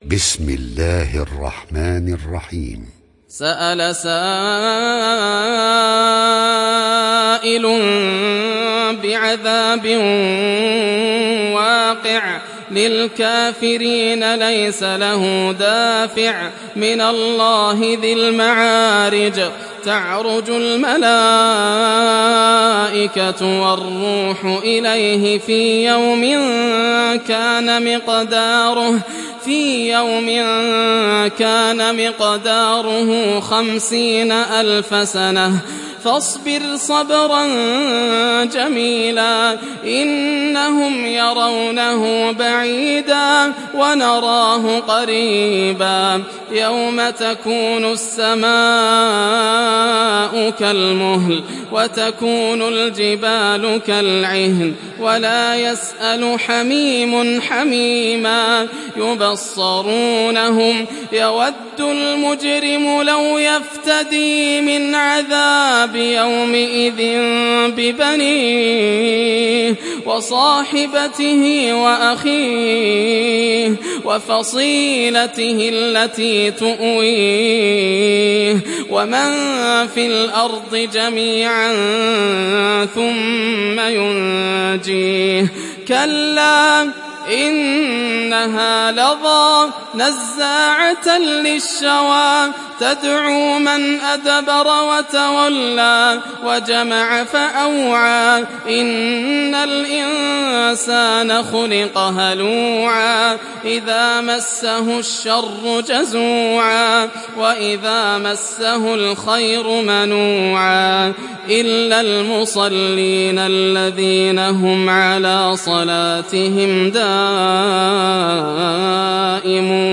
تحميل سورة المعارج mp3 بصوت ياسر الدوسري برواية حفص عن عاصم, تحميل استماع القرآن الكريم على الجوال mp3 كاملا بروابط مباشرة وسريعة